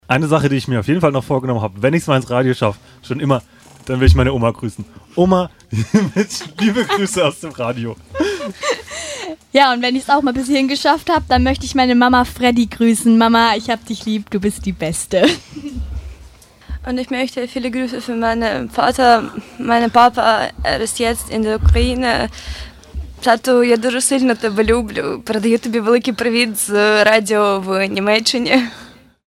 Finally, the editorial team prepared and performed a one-hour radio broadcast.